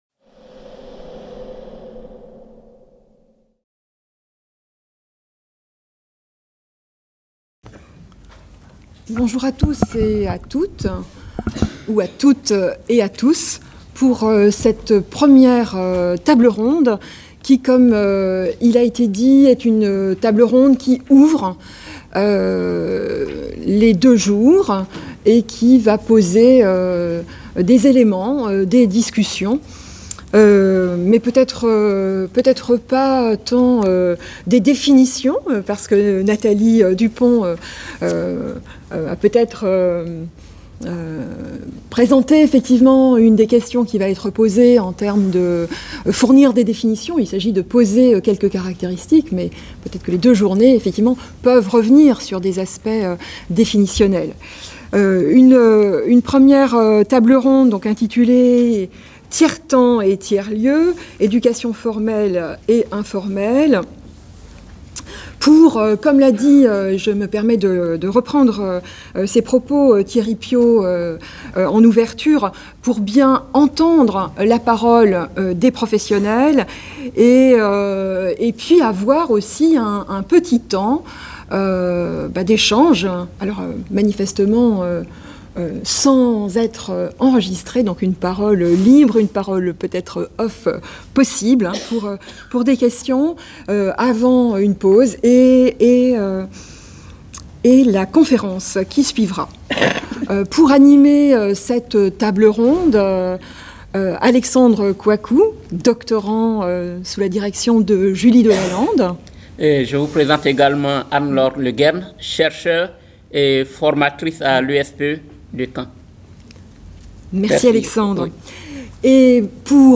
CIRNEF18 | 01 - Table ronde A : Tiers-temps et tiers-lieux, éducation formelle et informelle | Canal U